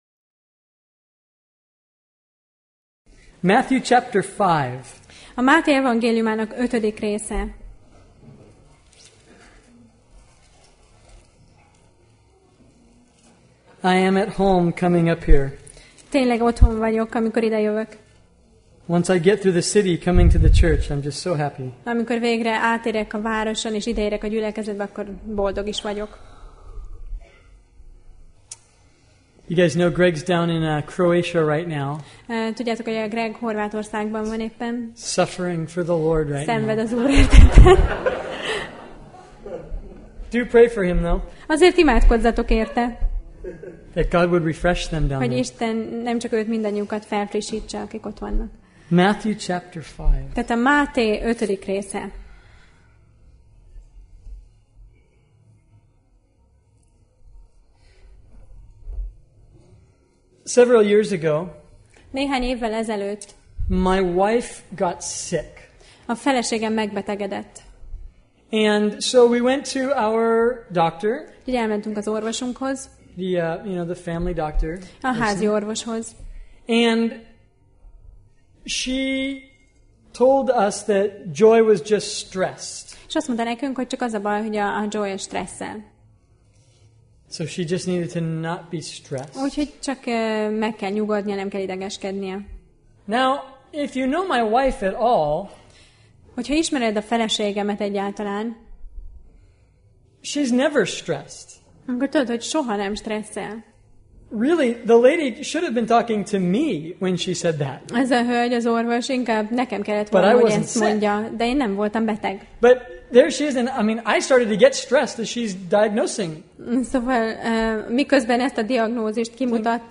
Sorozat: Tematikus tanítás Passage: Máté (Matthew) 5:21-48 Alkalom: Vasárnap Reggel